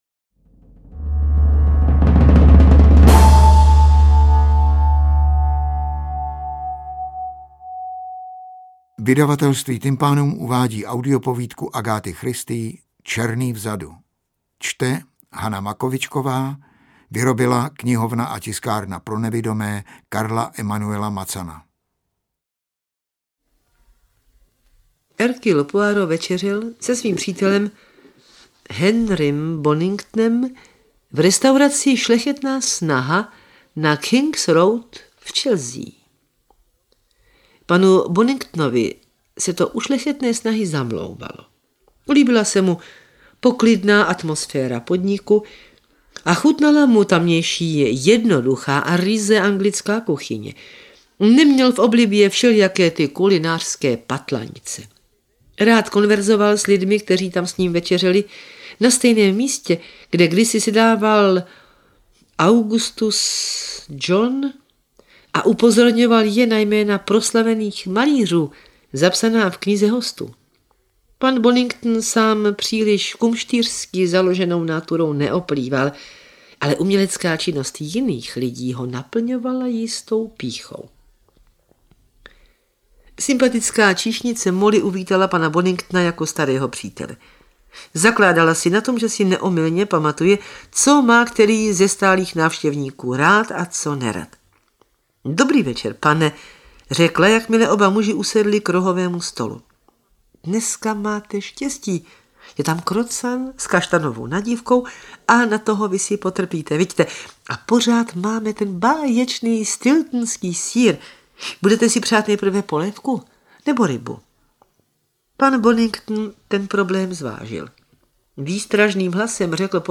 AudioKniha ke stažení, 5 x mp3, délka 45 min., velikost 44,1 MB, česky